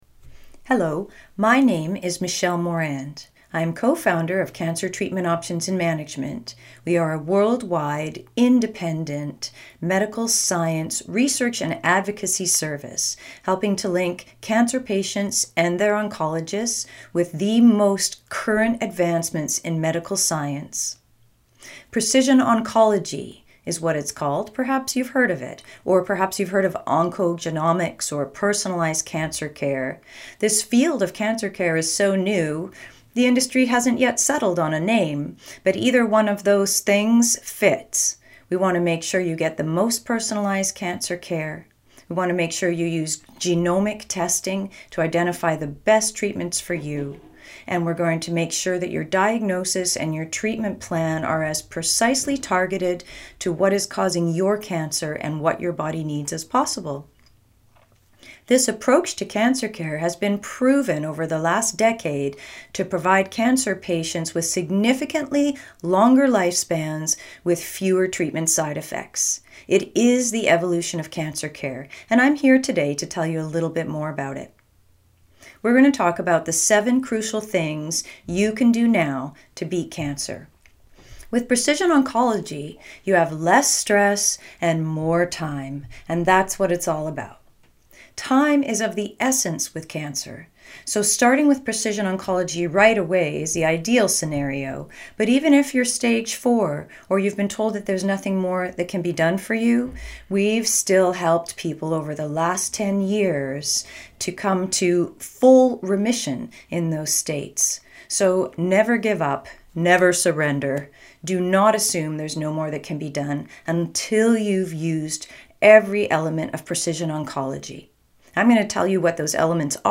Audiobook | 7 Crucial Things You Can Do To Beat Cancer (mp3)